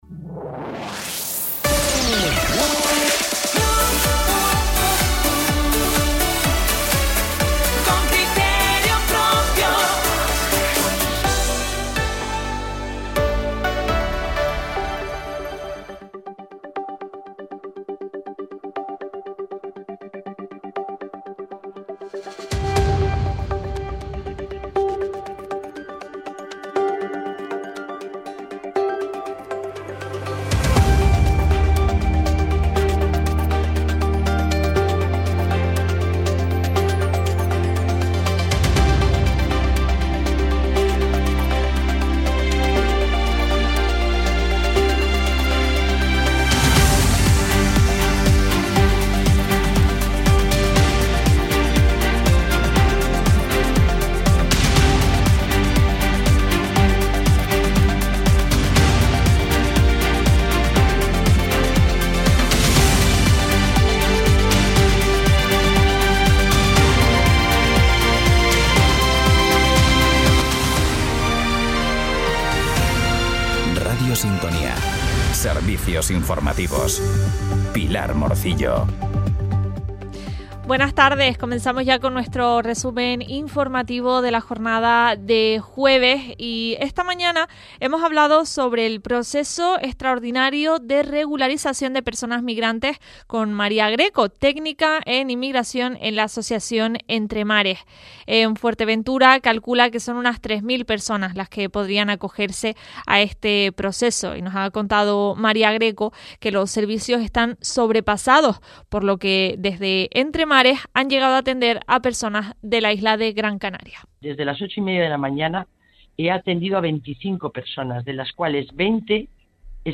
Informativos en Radio Sintonía - Radio Sintonía